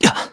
Siegfried-Vox_Damage_kr_01.wav